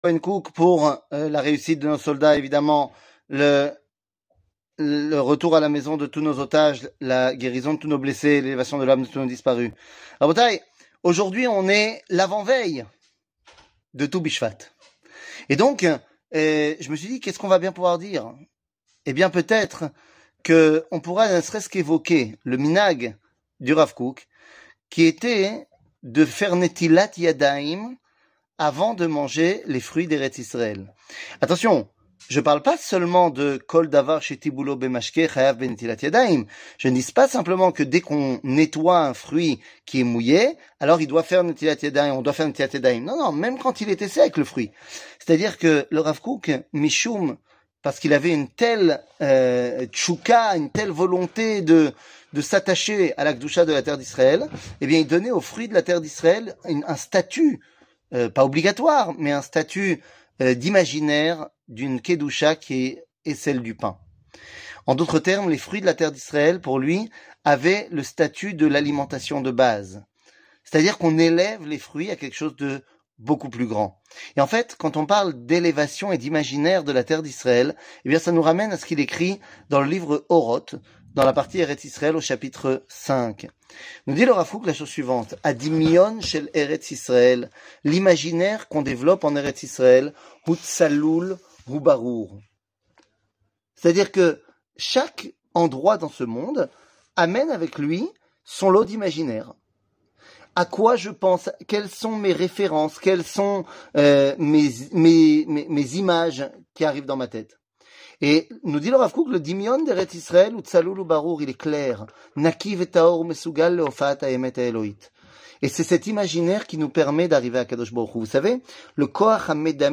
שיעור מ 23 ינואר 2024